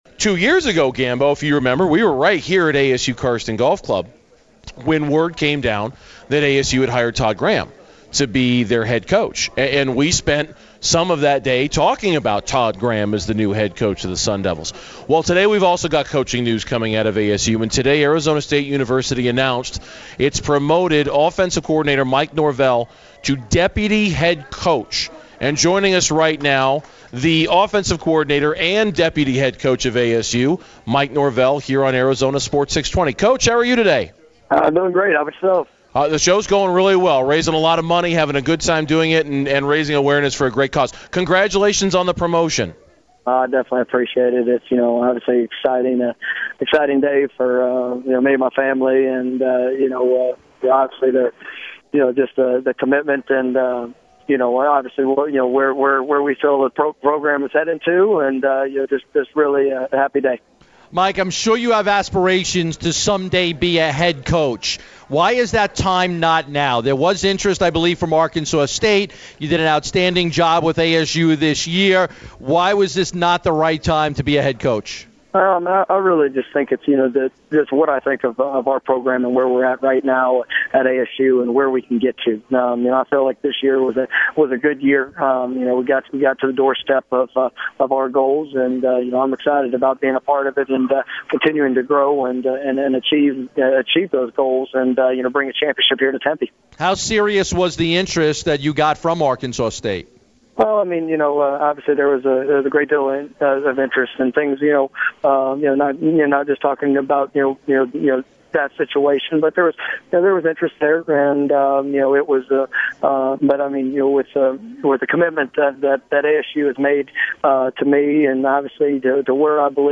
Here’s an interview with Mike Norvell on Burns & Gambo today.
Norvell-Interview.mp3